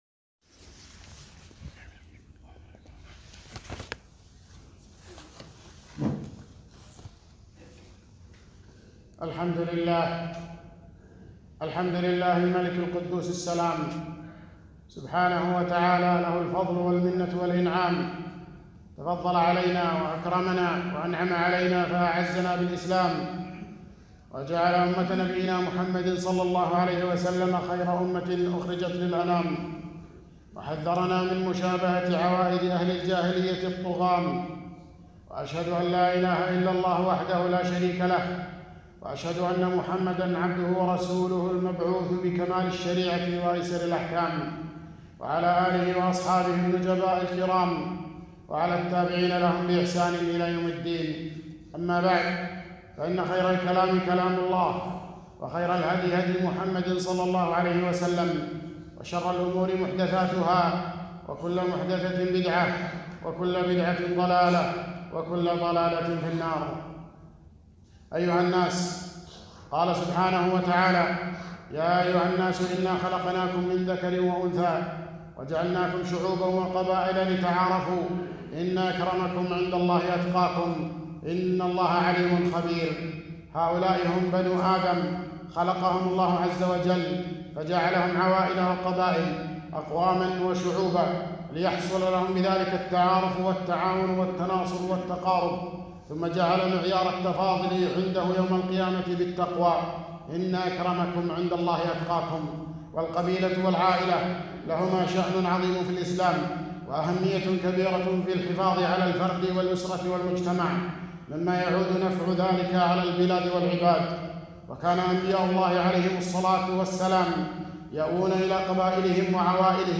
خطبة - التعصب القبلي 5-8-1442